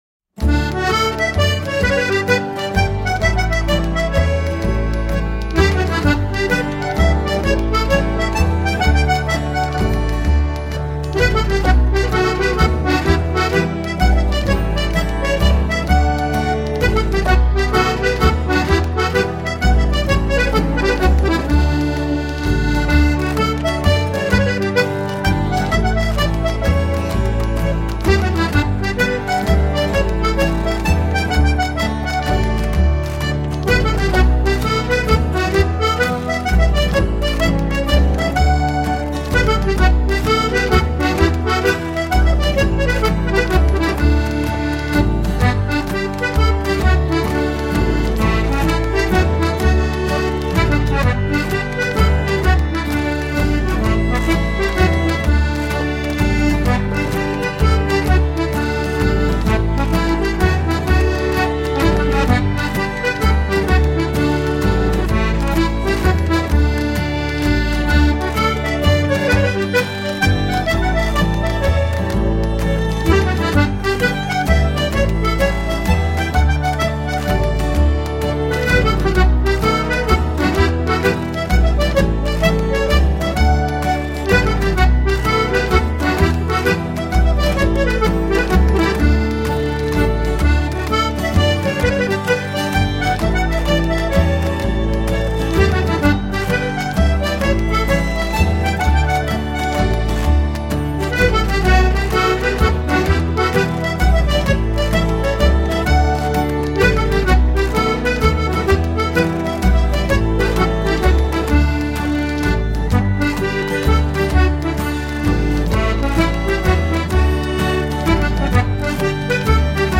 Accordéon / Accordion
Bodhran - Washboard - Bones